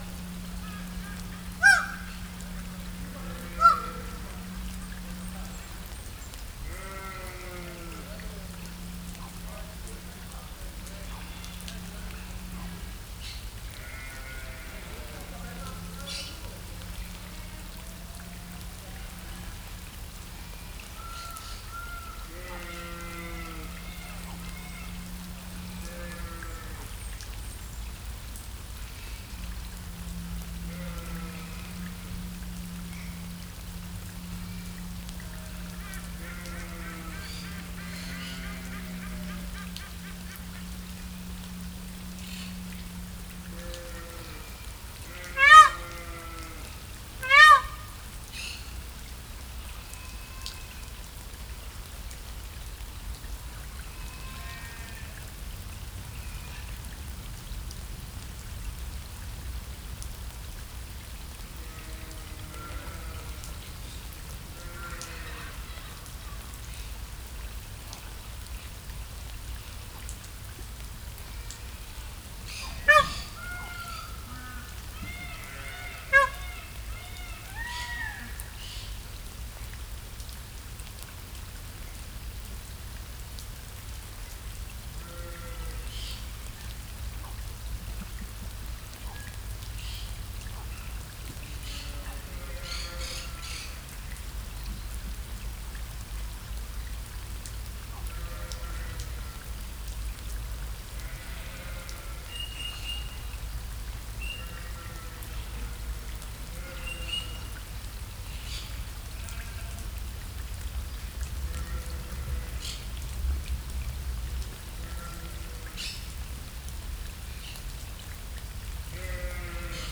Directory Listing of //allathangok/debrecenizoo2019_professzionalis/mesterseges_eso/
mestersegeseso_rikoltopavak0333.WAV